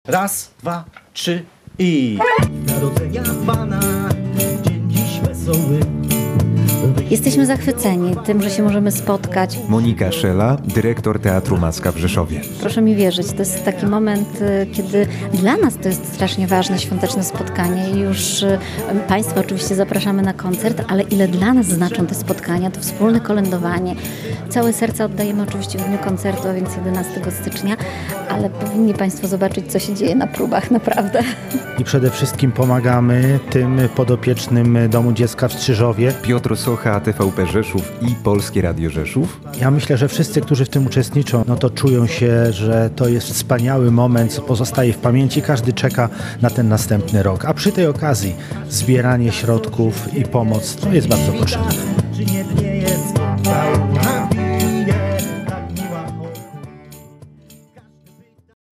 Próby do koncertu „Serca Sercom 2026” w Polskim Radiu Rzeszów
W Polskim Radiu Rzeszów trwa jedna z ostatnich prób przed koncertem „Serca Sercom 2026”. Już po raz siedemnasty w Auli Politechniki Rzeszowskiej odbędzie się charytatywny koncert, podczas którego rzeszowscy dziennikarze i aktorzy wspólnie zaśpiewają kolędy i pastorałki.